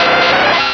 Cri de Tentacool dans Pokémon Rubis et Saphir.